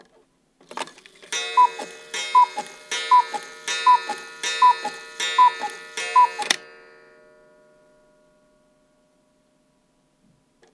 cuckoo_strike5.R.wav